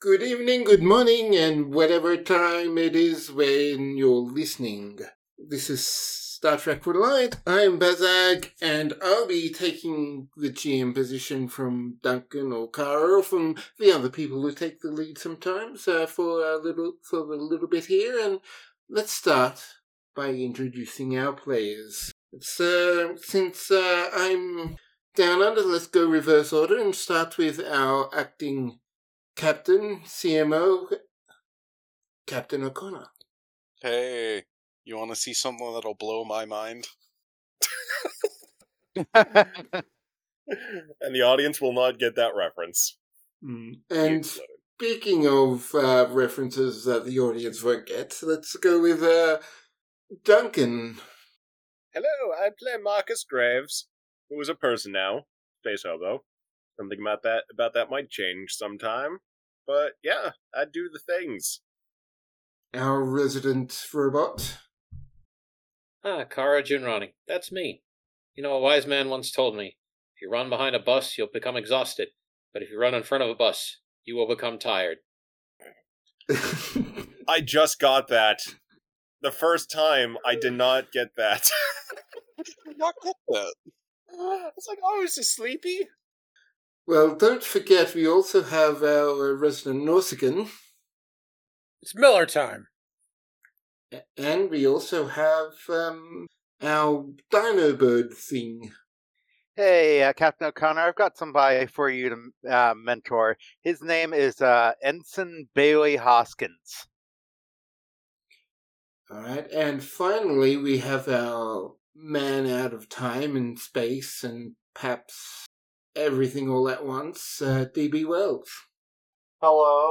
Star Trek: Reliant (RPG Live Play) Star Trek: Reliant - Night on the Villa Express Play Episode Pause Episode Mute/Unmute Episode Rewind 10 Seconds 1x Fast Forward 30 seconds 00:00 / 1:36:12 Subscribe Share